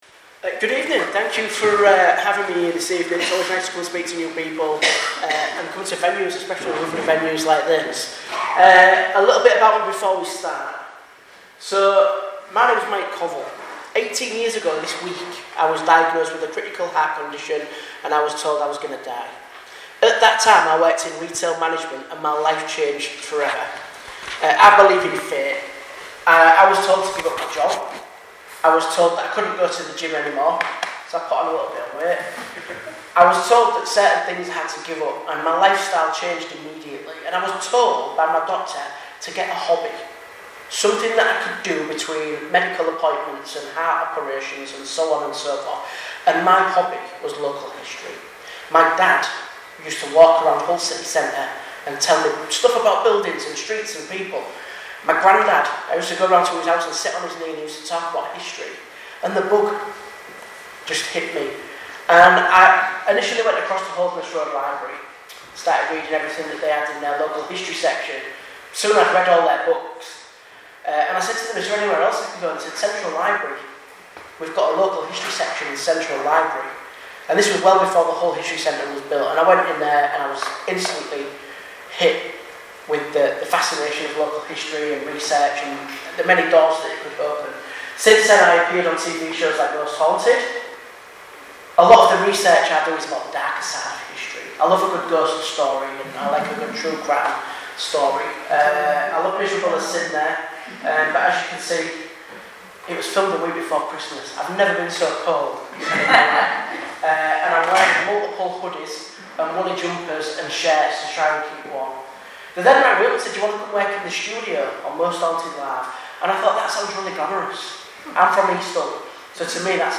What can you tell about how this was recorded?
The Annual General Meeting of the Swanland Heritage Centre took place on Thursday 25th July 2024 at 7pm in the Swanland Village Hall This year has marked the 100th anniversary of the death of Sir...